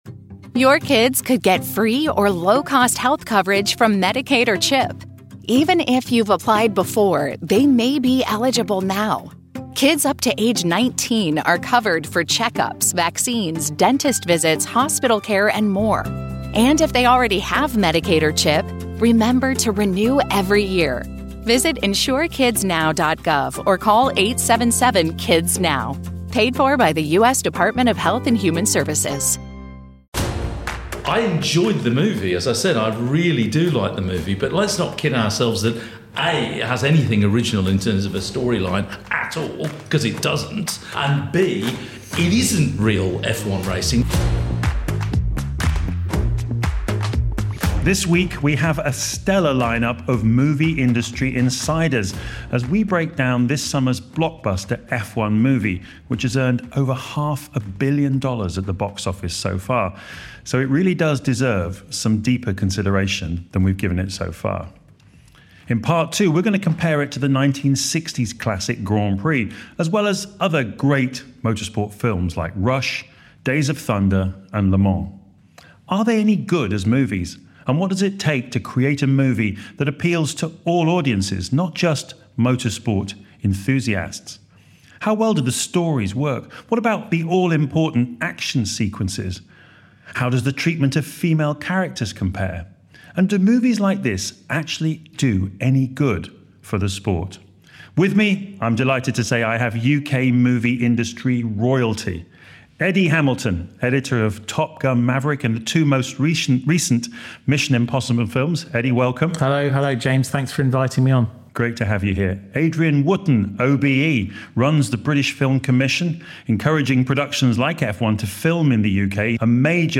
This week we gather a stellar line-up of movie industry insiders as we break down the summer blockbuster F1 Movie, which has now earned over half a billion dollars at the box office, so deserves some deeper consideration.